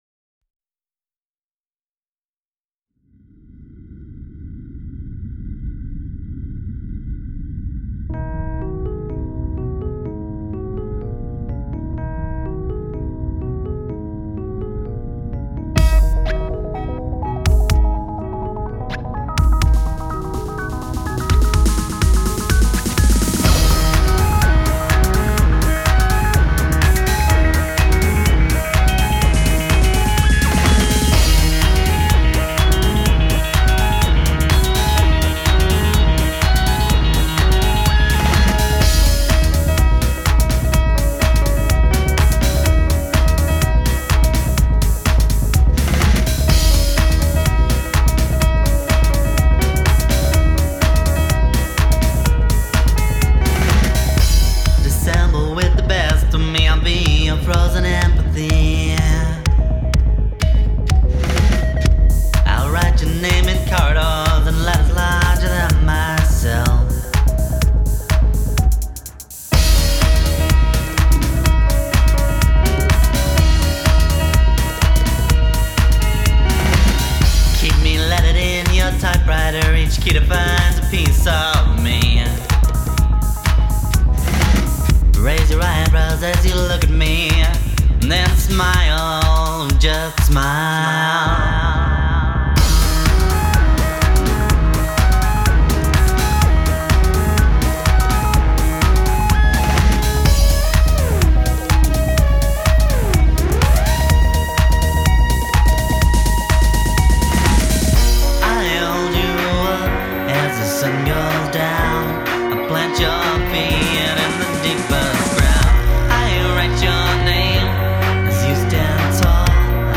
Songs - only rough demos, but they'll give you the idea:
A funky house remix